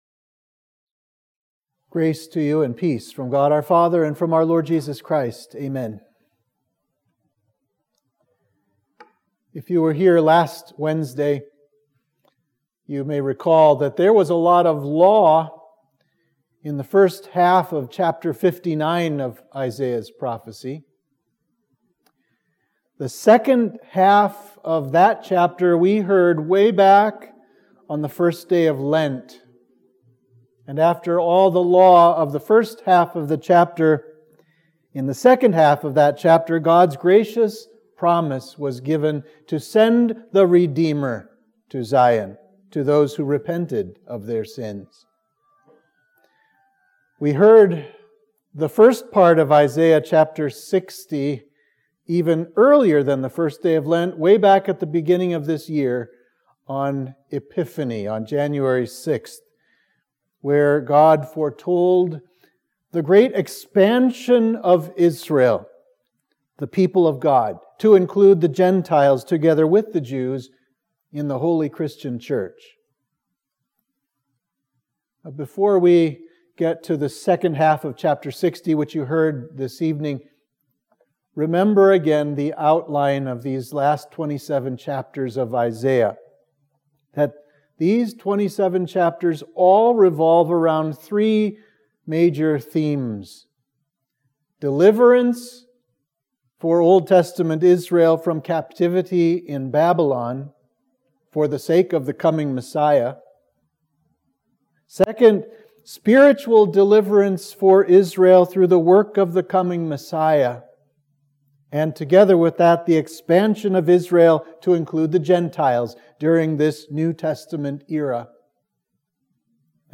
Sermon for Midweek of Trinity 18